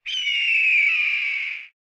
Звуки ястреба